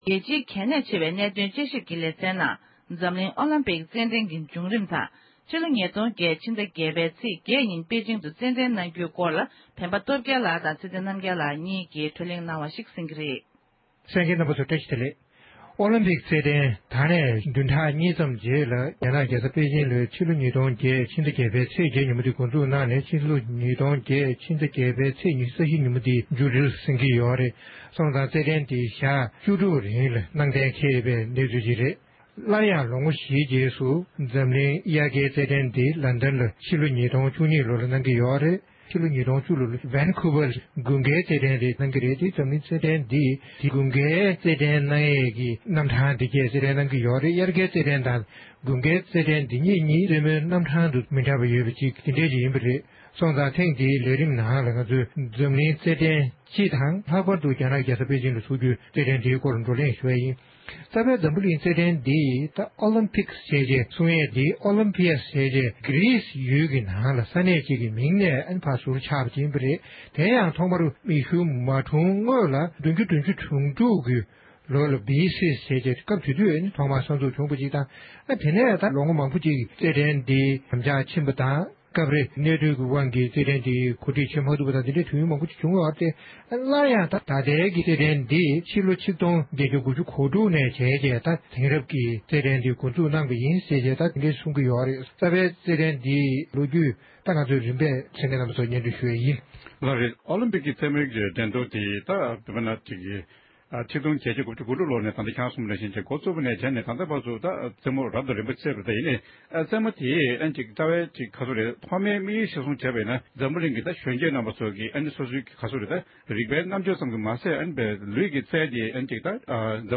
བགྲོ་གླེང